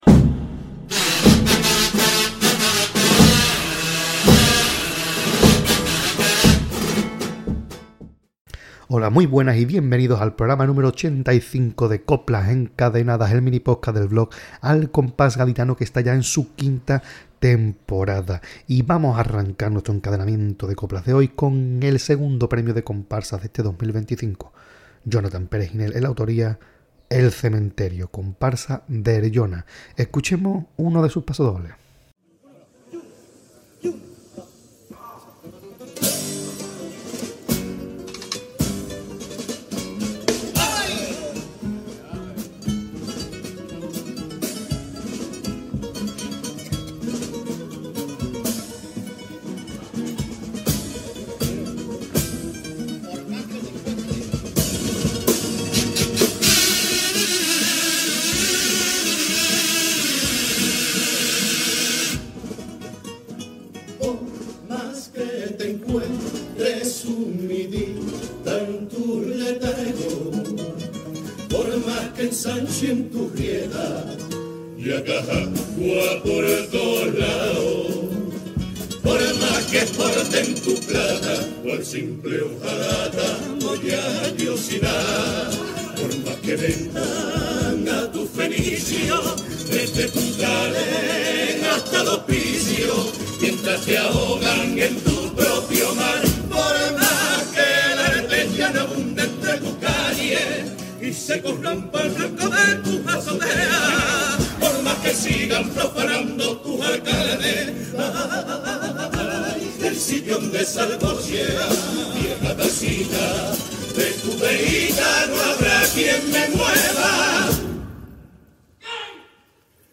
Pasodoble